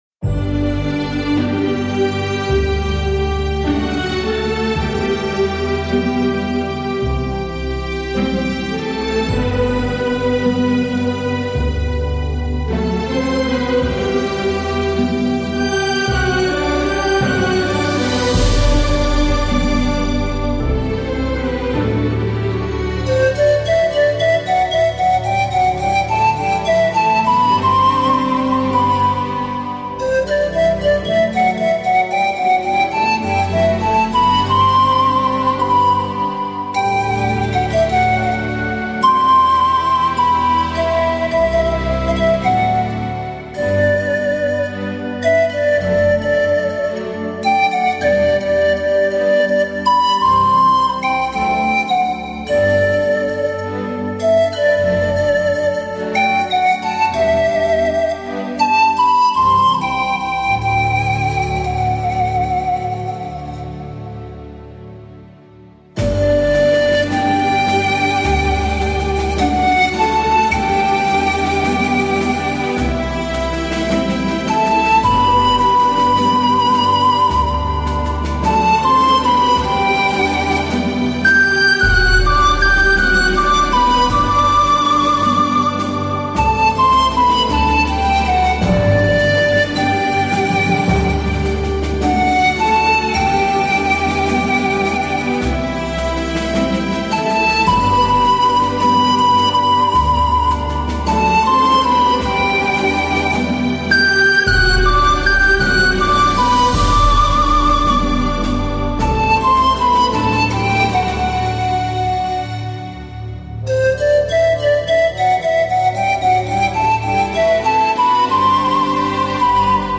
的排箫音乐在悠扬中隐藏着哀伤，悠扬清越，充满诗意，听者无不动容。